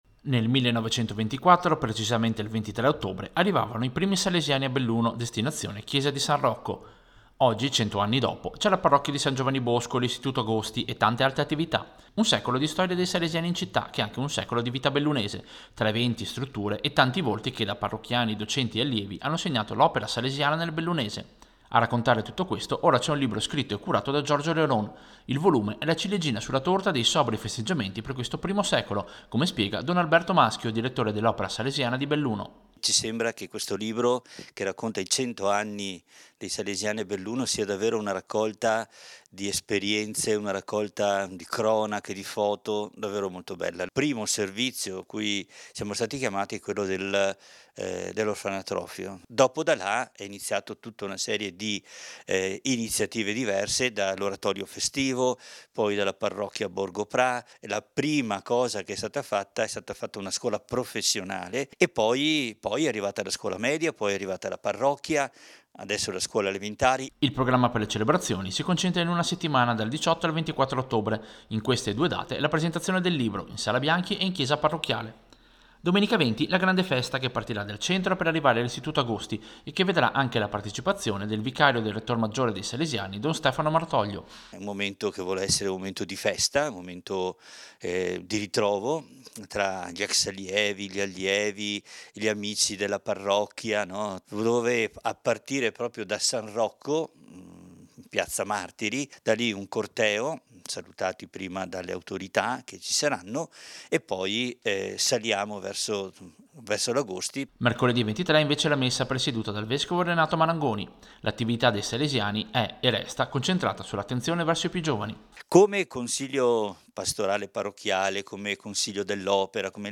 Servizio-100-anni-salesiani-Belluno.mp3